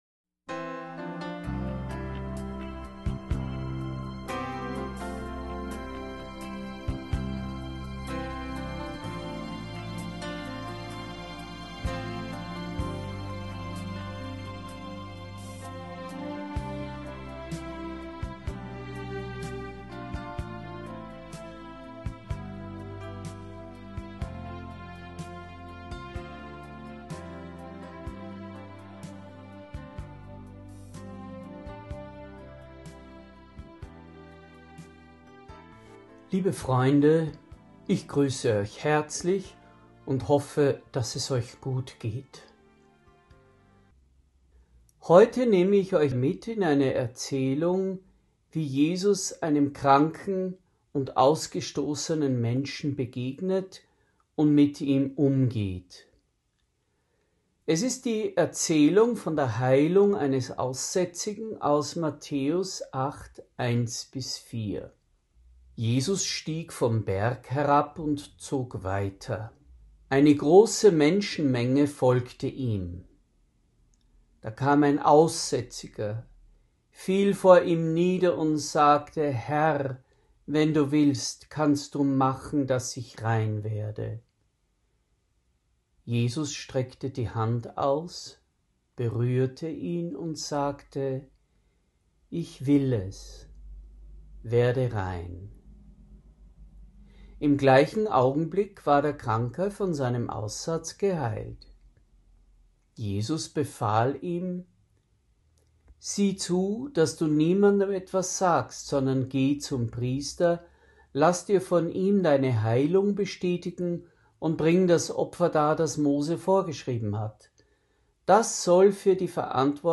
Predigt | NT01 Matthäus 8,1-4 Die Heilung eines Aussätzigen – Glauben und Leben